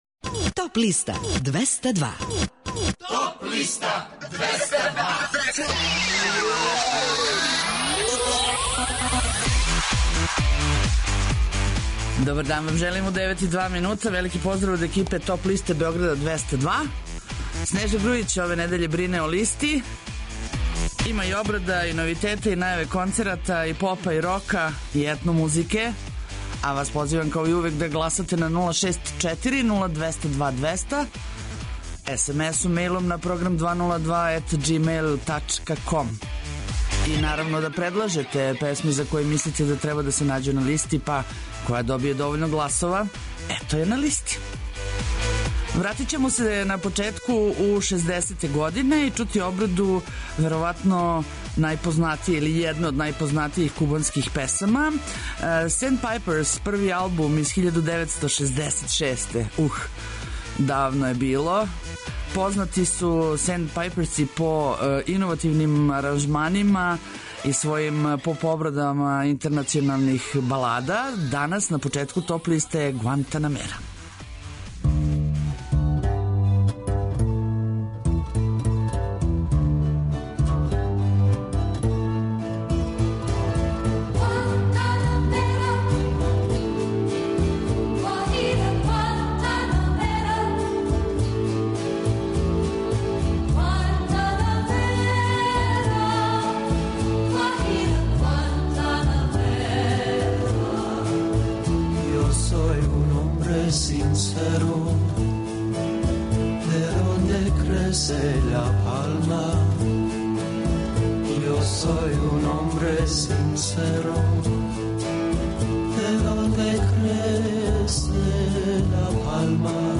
У овонедељном издању Топ листе у 2016. години слушајте новогодишње и божићне музичке нумере, иностране и домаће новитете, као и композиције које су се нашле на подлисти лектира, класика, етно, филмскe музикe...